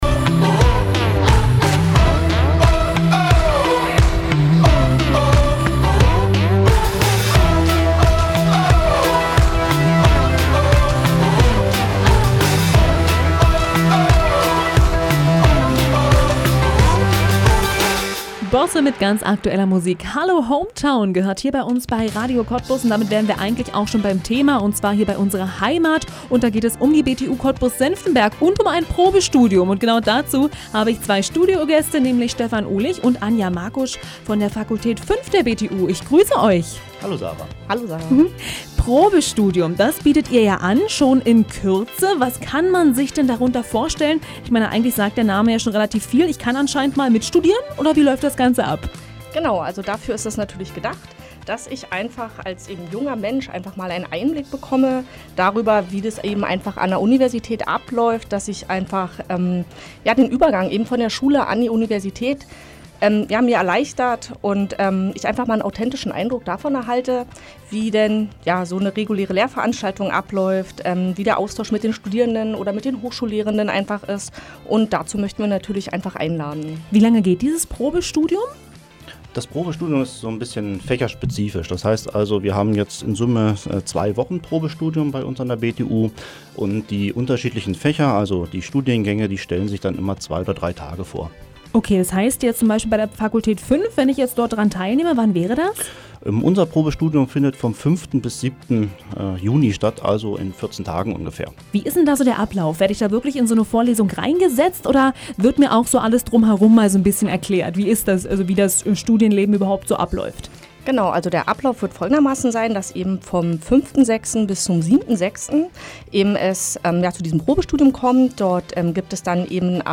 Live-Mitschnitt Radio Cottbus zum Probestudium der Fakultät 5